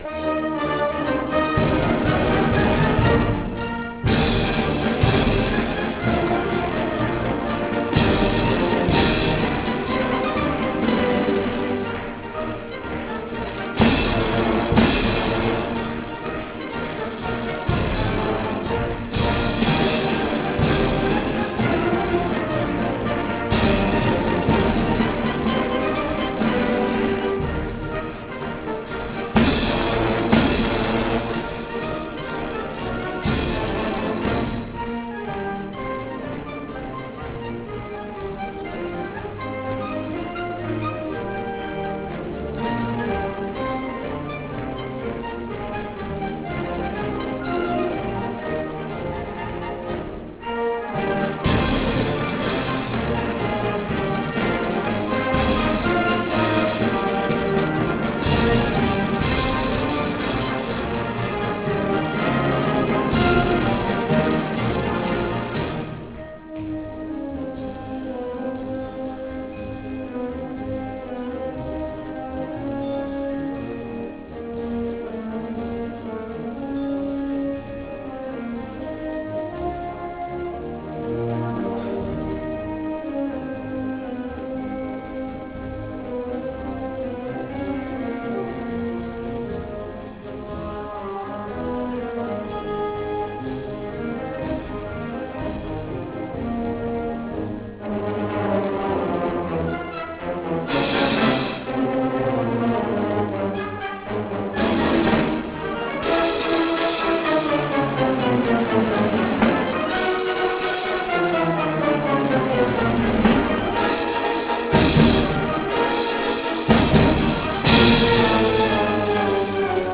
Se você encontrou problemas ao reproduzir a marcha